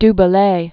(d bə-lā, dü bĕ-lā), Joachim